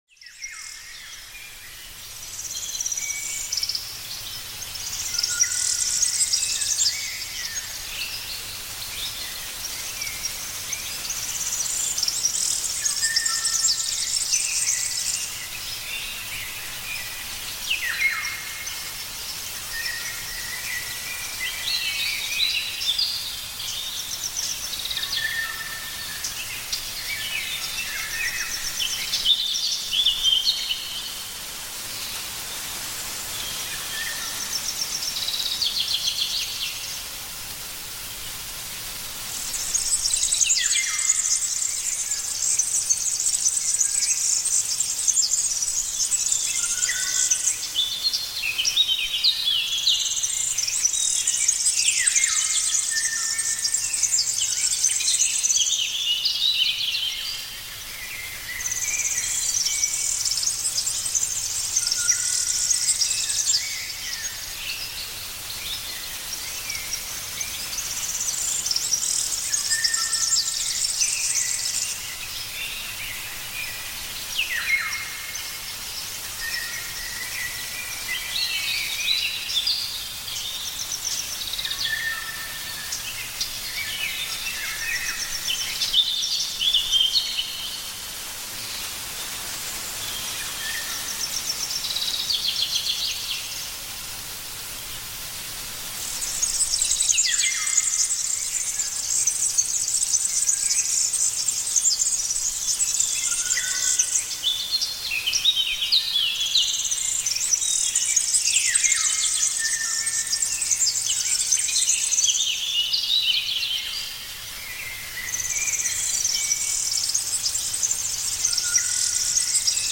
Naturgeräusche